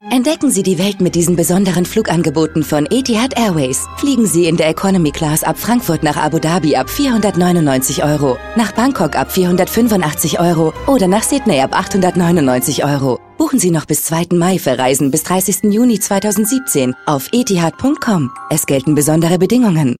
Sprechprobe: Werbung (Muttersprache):
Funk Spot_Etihad Airways.mp3